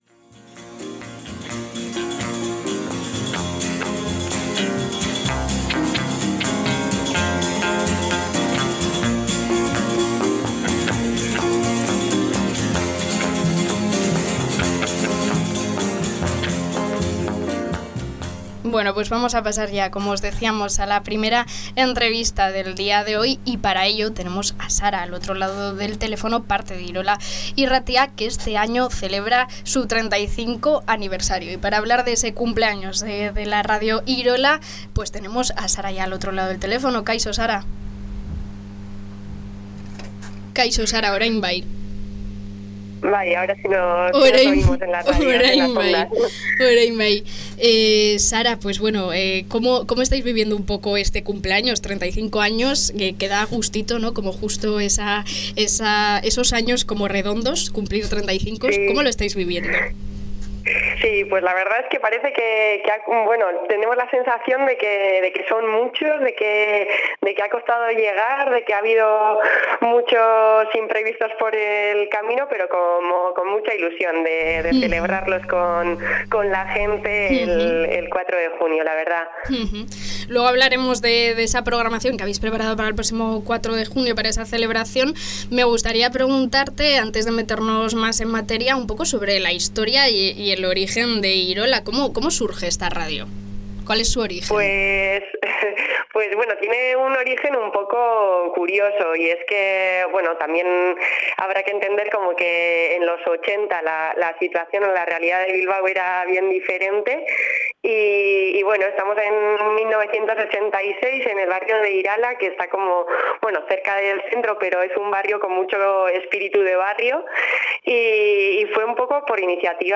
Aquí podéis escuchar la entrevista completa: